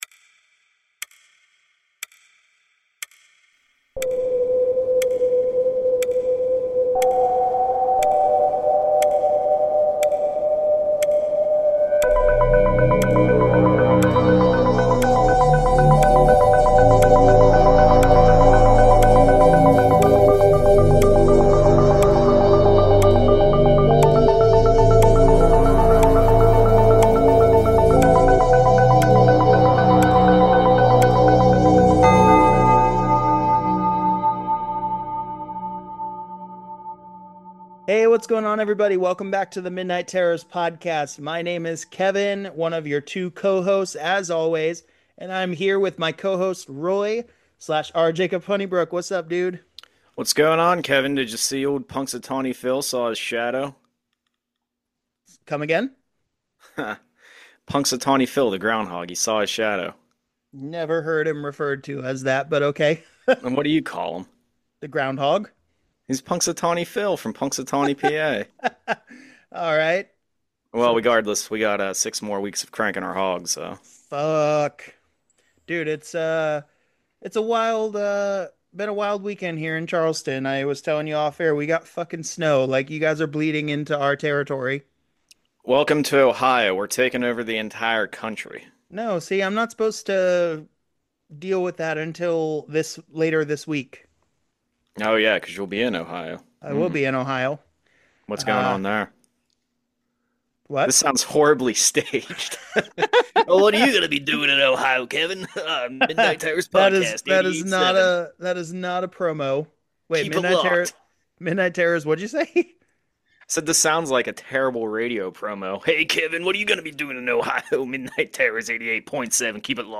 A podcast hosted by friends and family discussing all things horror!